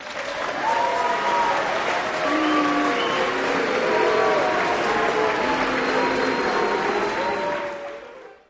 Crowd Boo, Yeah, Left and Right, End Speech